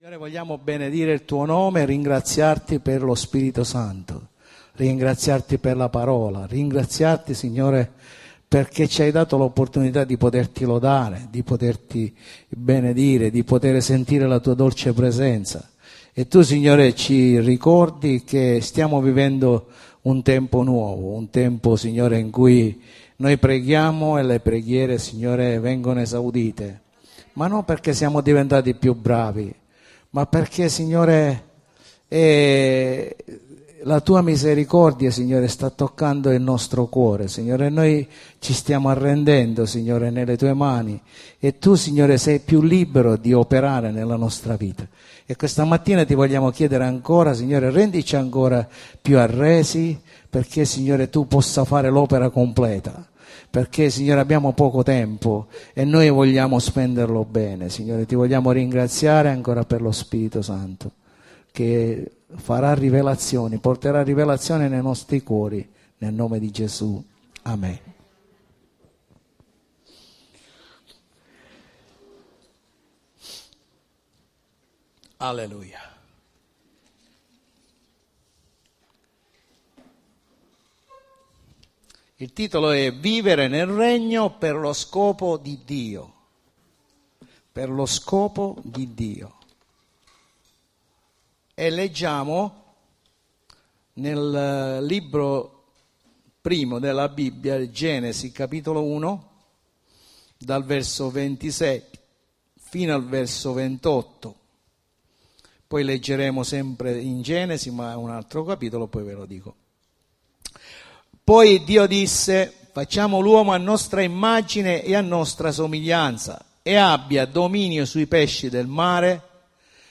Sezione del sito per l'ascolto dei messaggi predicati la domenica e per il riascolto di studi biblici
Predicazione